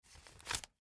Звуки бумажных денег
Звук вытягивания купюры из кучки и протягивания рукой